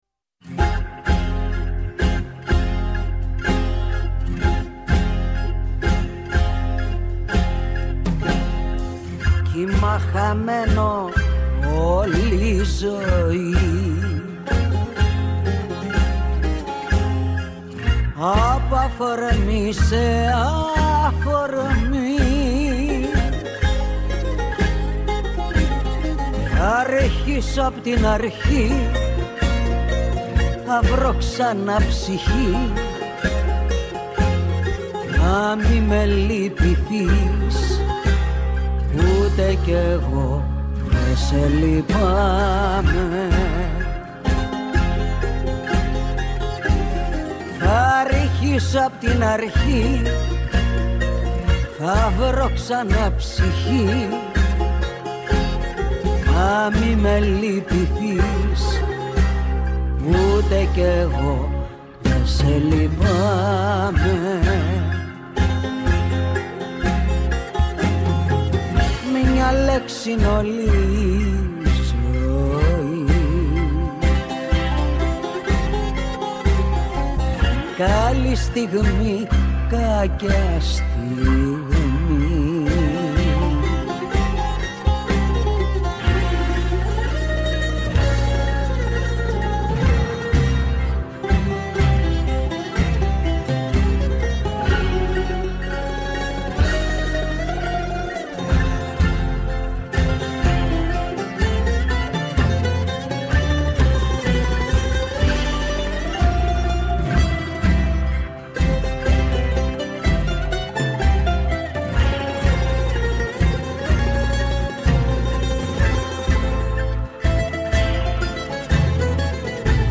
Ακούστε, μια διαφορετική ραδιοφωνική συνομιλία